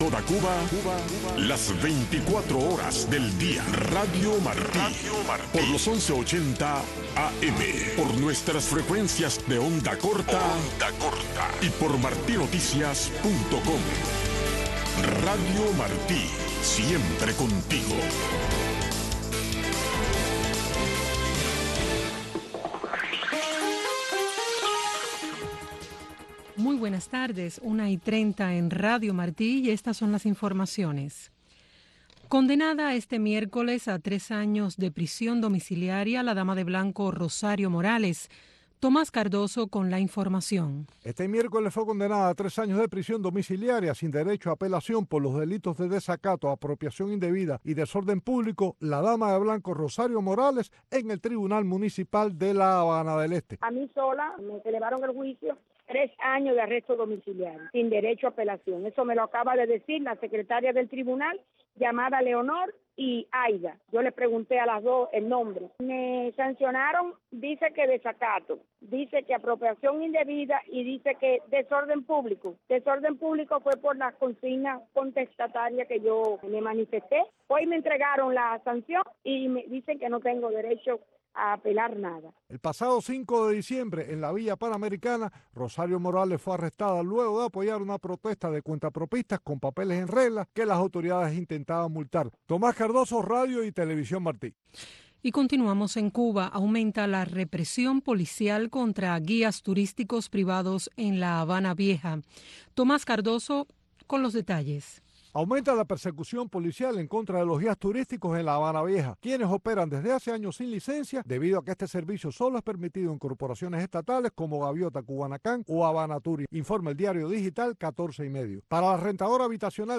“Ventana a Miami”, un programa conducido por el director de la oficina de transmisiones hacia Cuba, Tomás Regalado, te invita a sintonizarnos de lunes a viernes a la 1:30 PM en Radio Martí. “Ventana a Miami” te presenta la historia de los cubanos que se han destacado en el exilio para que tú los conozcas.